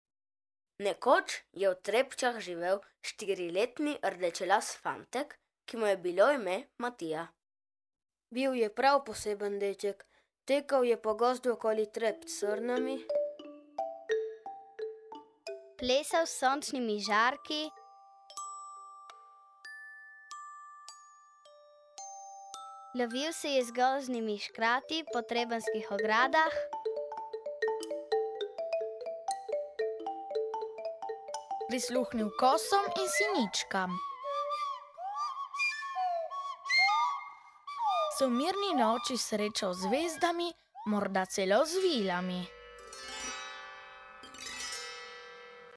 ZIMSKA GLASBENA PRAVLJICA
Besedilo in zvoki - 716 KB